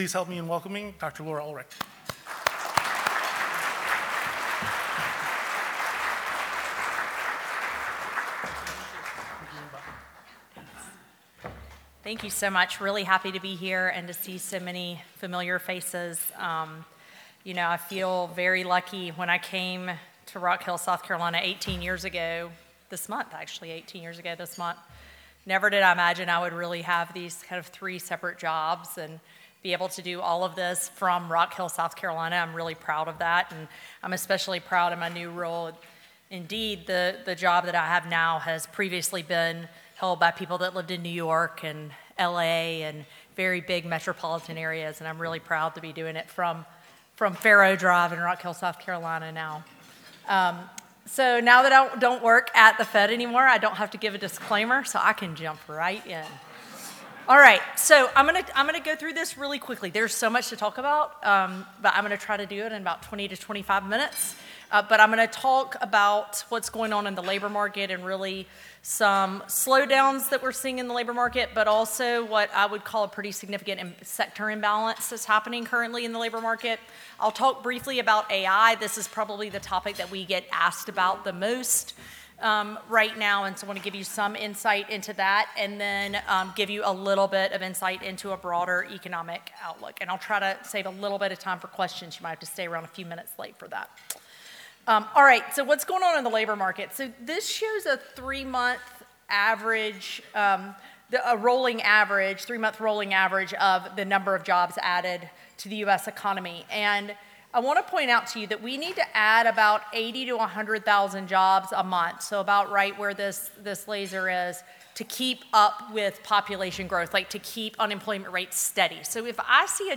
She was a guest speaker at the I-77 Alliance meeting in Richburg.